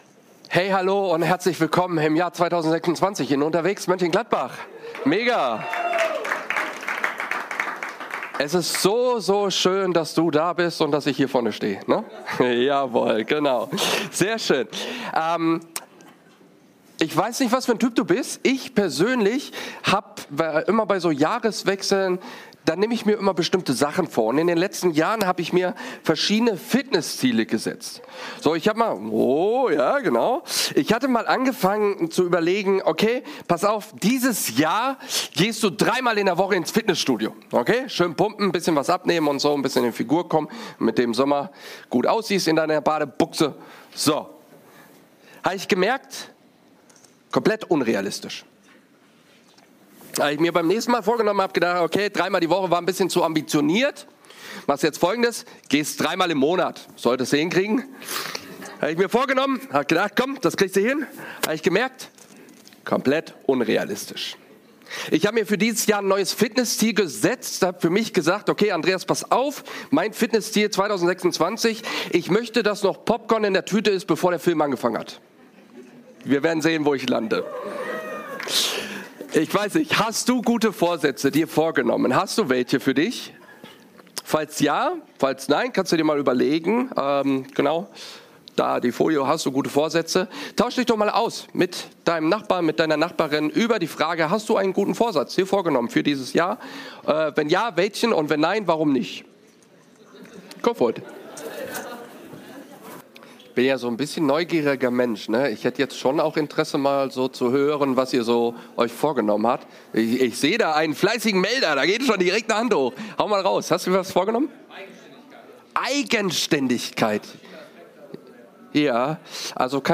Predigt-Podcast